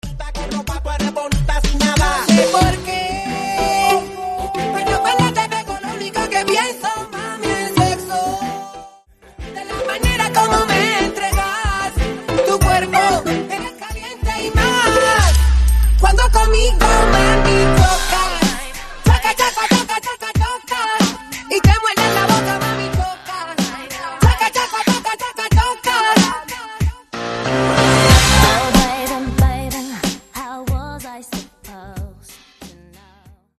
Mashup , Transición